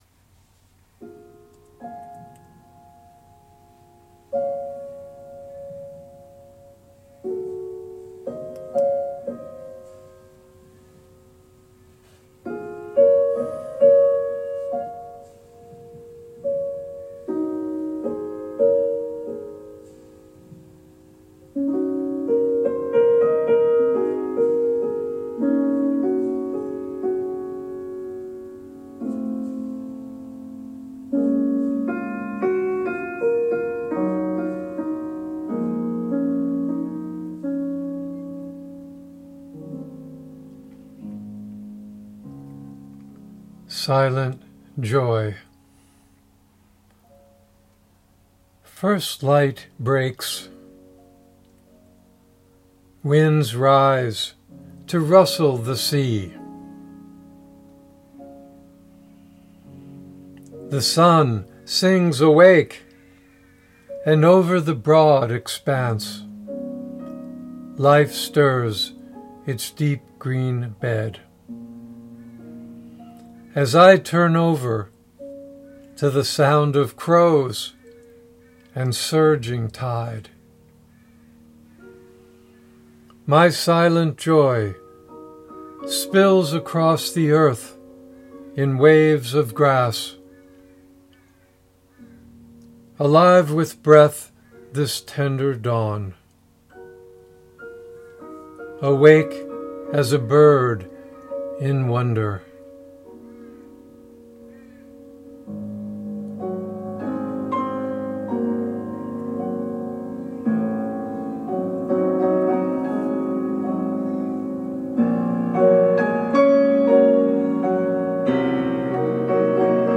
Reading of “Silent Joy” with music by Claude Debussy